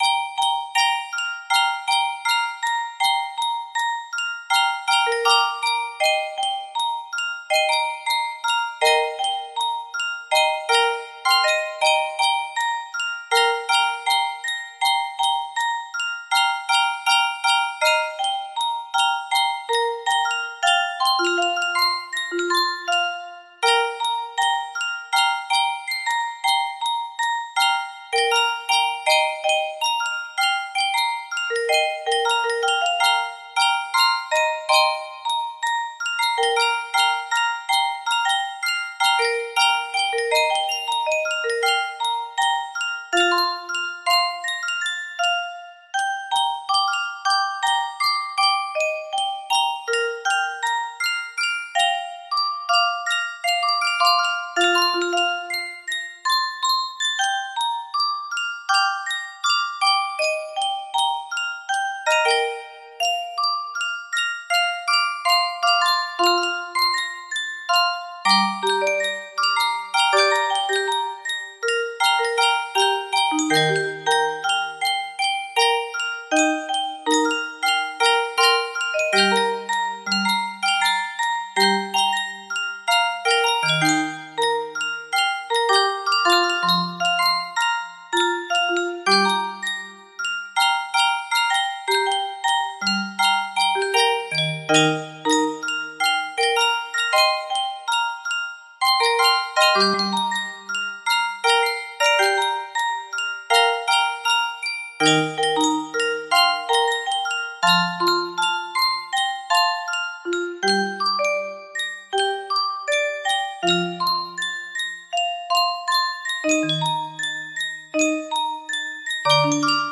Full range 60
Done, Proper tempo, No Reds.